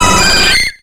Cri de Leveinard dans Pokémon X et Y.